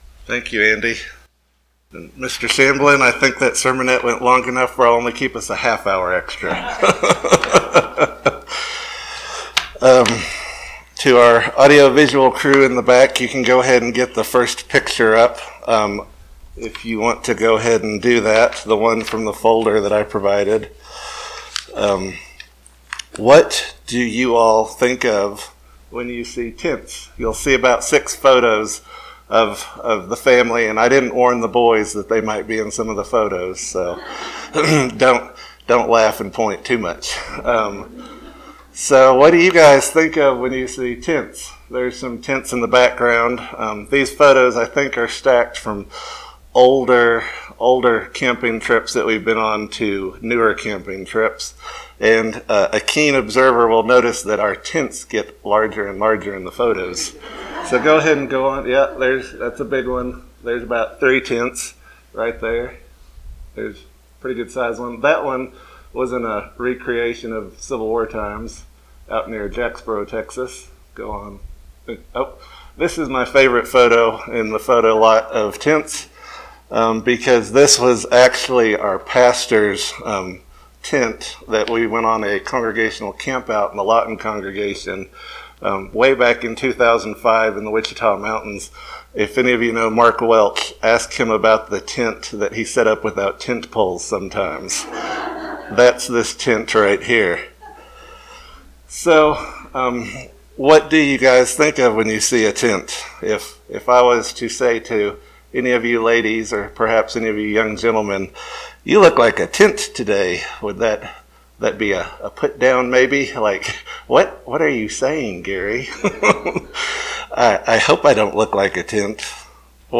We as Christians can take lessons from the Old Testament tabernacle and apply those lessons in our lives today to improve our walk with God. This sermon explores these lessons in the backdrop of the upcoming Feast of Pentecost.
Given in Lubbock, TX